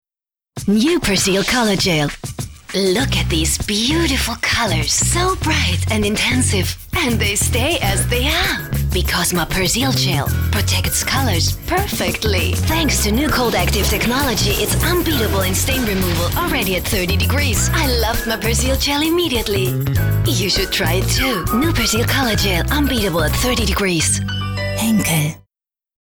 deutschsprachige Sprecherin,Sängerin, vielseitig einsetzbar, Werbung, von jung bis alt, spielerisch, Comic, Zeichentrick, Dokumentationen, Telefonanlagen, Hörbücher- u. Spiele,, Gesang, OFF- Sprecherin ATV,
Sprechprobe: Sonstiges (Muttersprache):